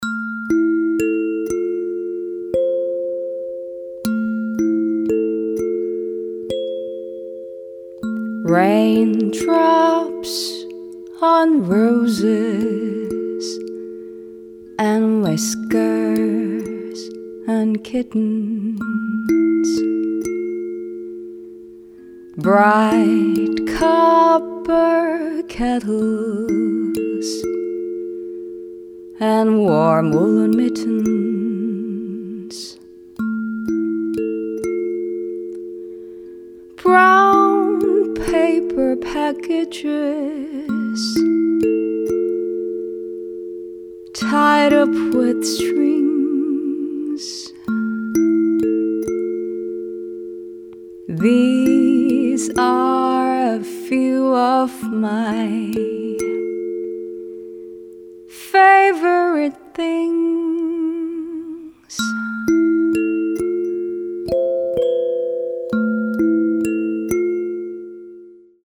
• Качество: 320, Stereo
интригующие
Blues
инструментал
Интригующая мелодия